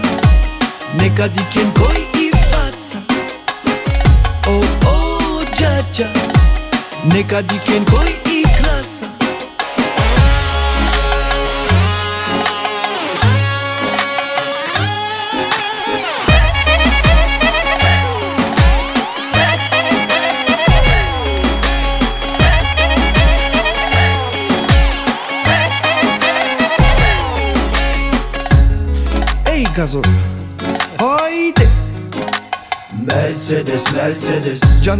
Скачать припев